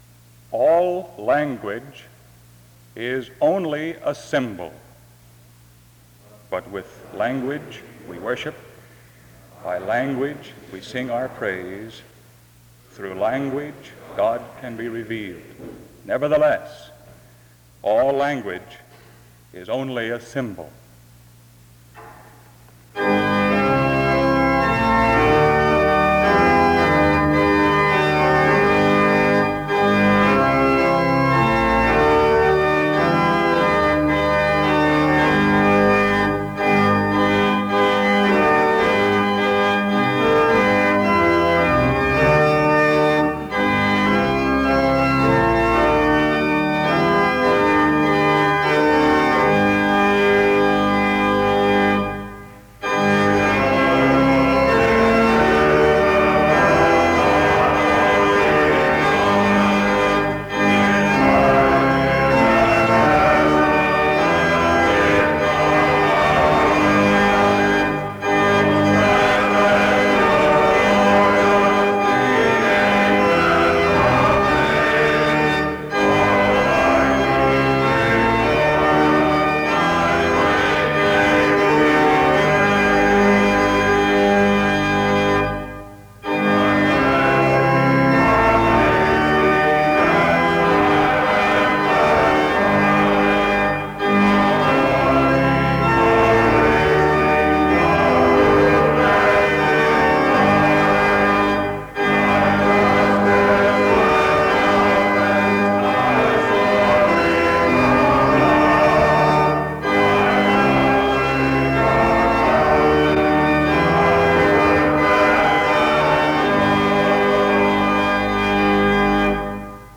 Download .mp3 Description The service starts with opening remarks and music from 0:00-3:16. A prayer is offered from 3:24-5:31. The speakers perform their recitations from 5:37-19:21. This service was organized by the Student Coordinating Council and consists of public readings or recitations of scripture passages in various languages.